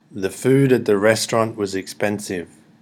thefoodattherestaurantFAST.mp3